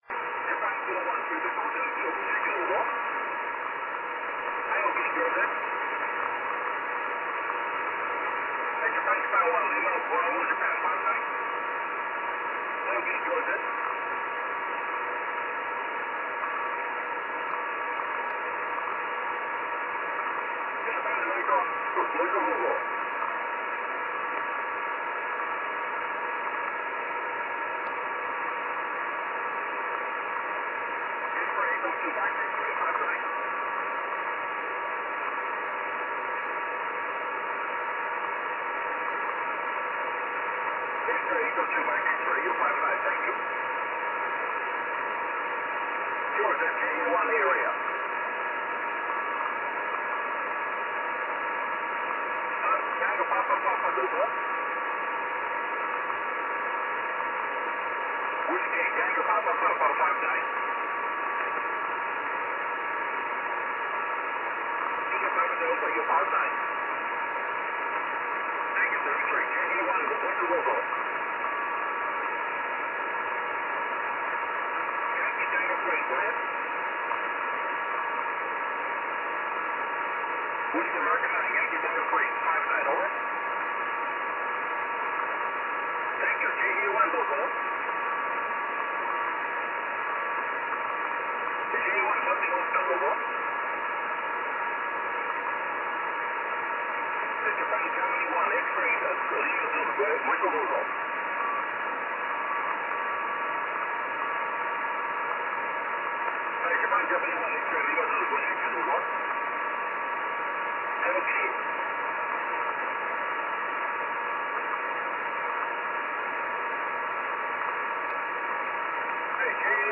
Mar/21 2151z 9Q0HQ 21.295MHz SSB